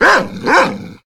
bdog_attack_6.ogg